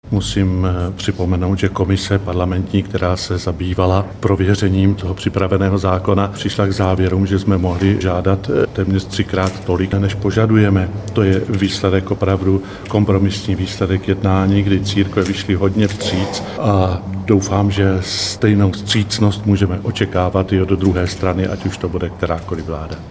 Předseda katolického episkopátu, olomoucký arcibiskup Jan Graubner k tomu podotkl, (audio MP3)